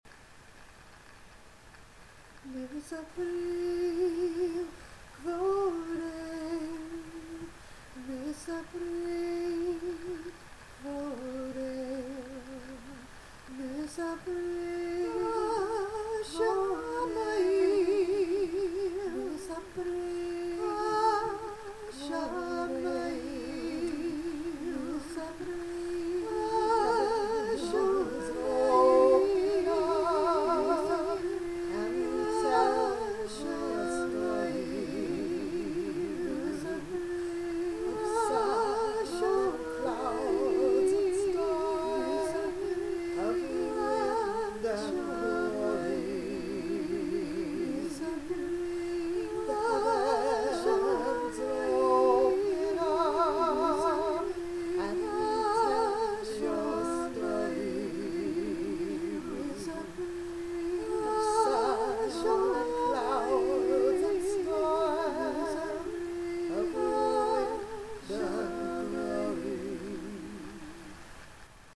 To hear the various parts of the chant, use the audio players.